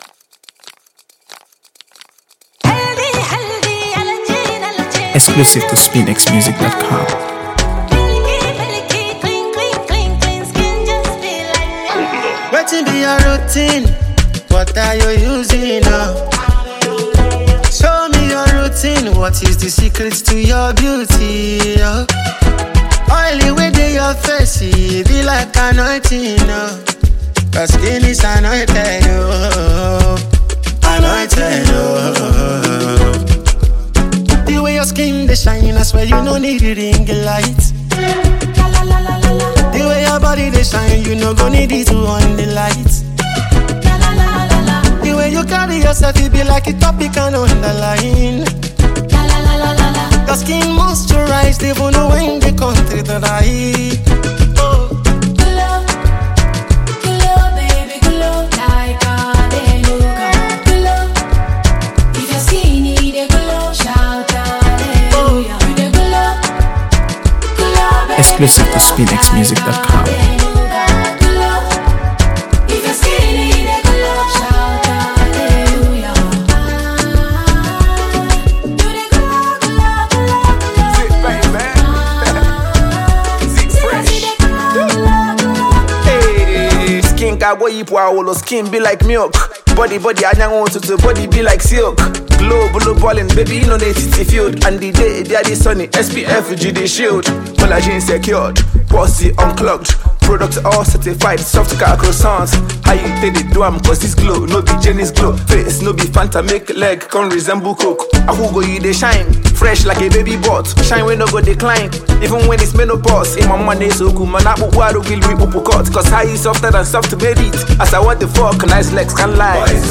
AfroBeats | AfroBeats songs
radiates sensuality and artistry